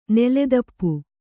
♪ neledappu